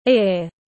Cái tai tiếng anh gọi là ear, phiên âm tiếng anh đọc là /ɪər/.
Ear /ɪər/